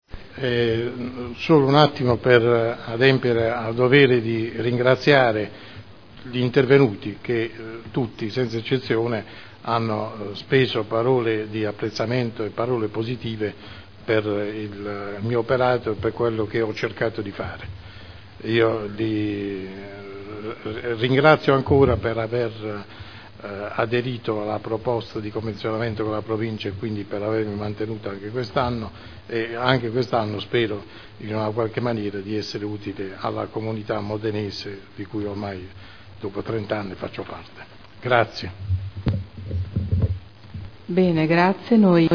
Seduta del 24 febbraio. Relazione del Difensore Civico al Consiglio Comunale sull’attività svolta nell’anno 2013.